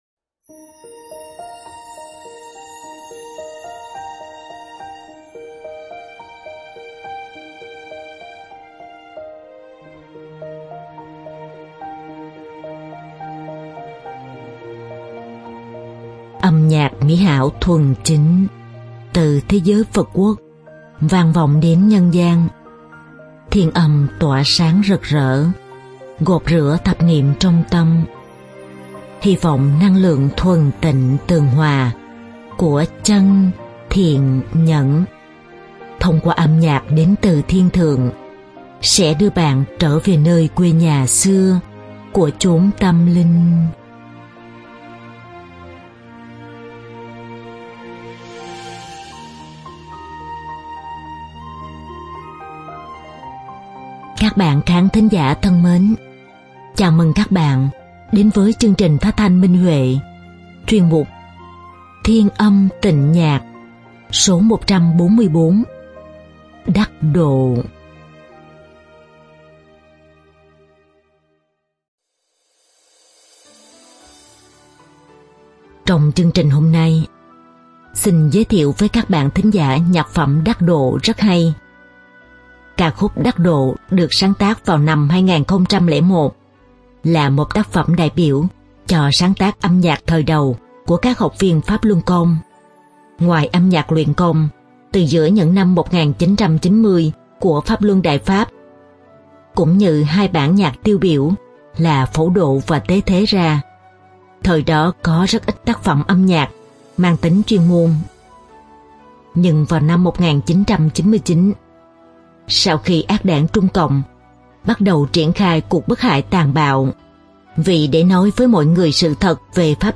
Ca khúc
Diễn tấu cổ tranh